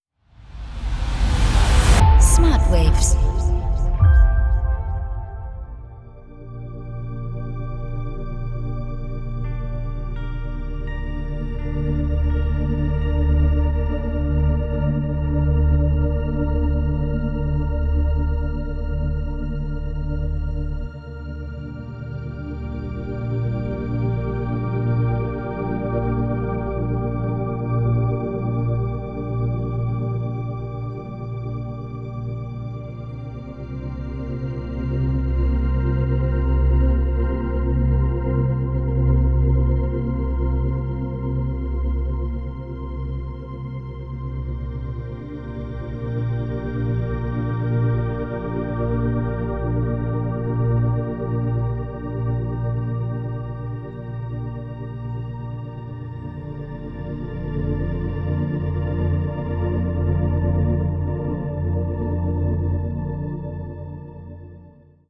0,5-4 Hertz Delta Wellen Frequenzen